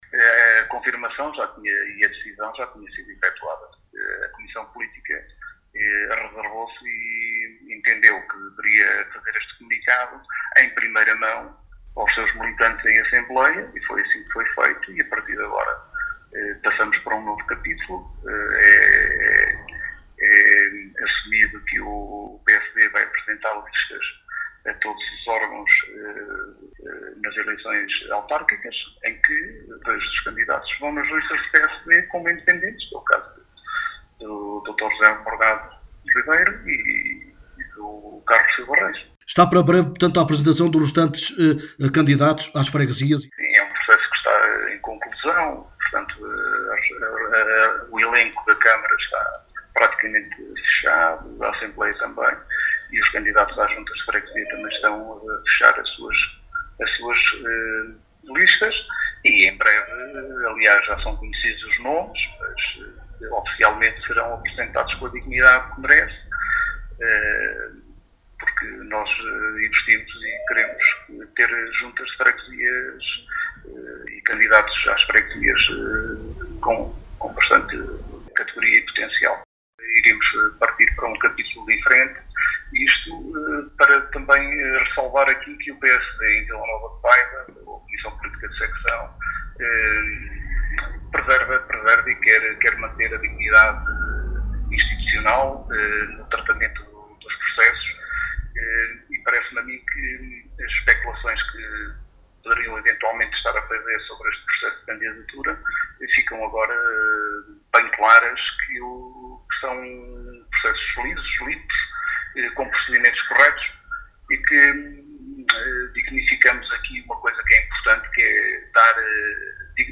em declarações à Alive FM